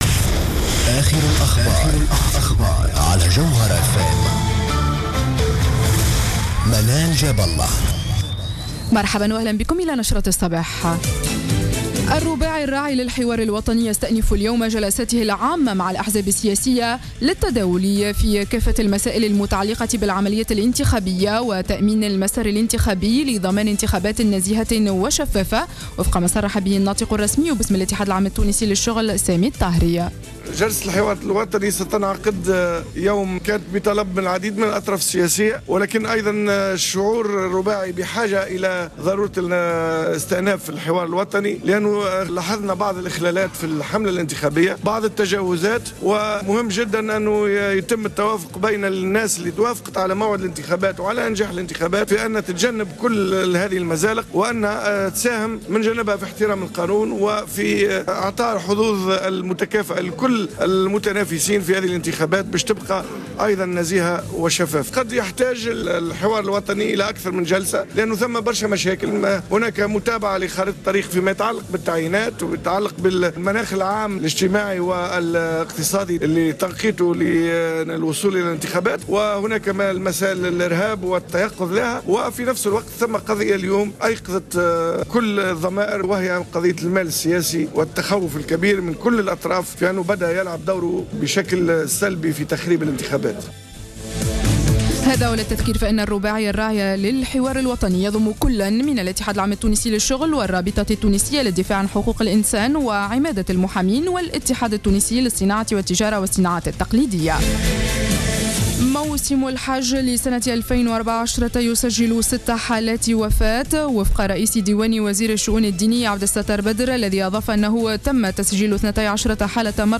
نشرة أخبار السابعة صباحاً ليوم الإثنين 13-10-14